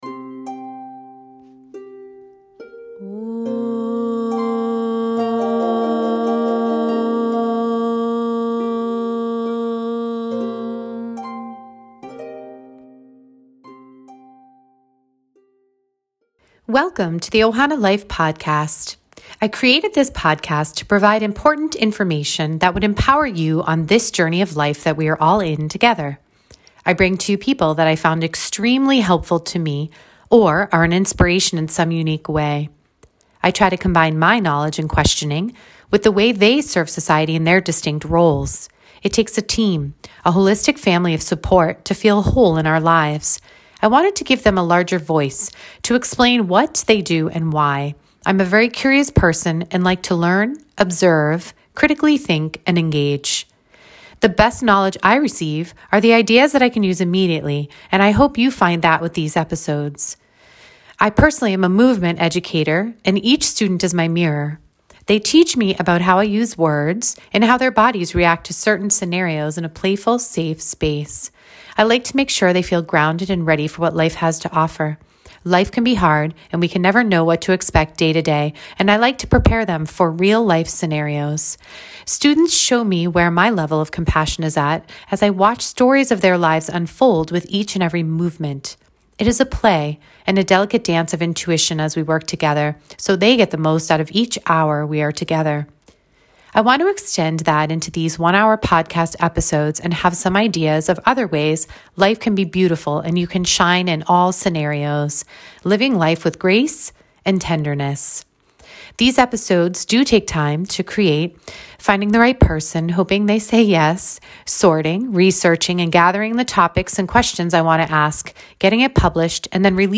In this episode I had the honor of interviewing